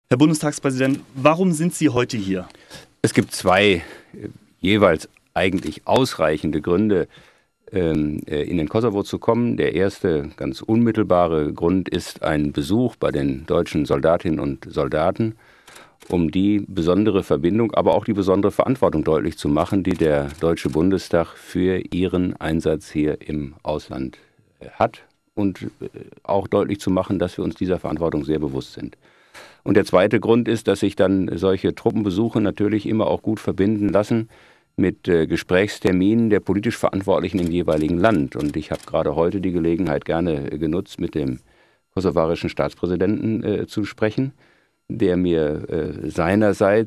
Bundestagspräsident Lammert im Interview mit Radio Andernach, 9min, 8,2MB, mp3, 8.4 MB Link (öffnet neues Fenster)
interview_lammert_kosovo.mp3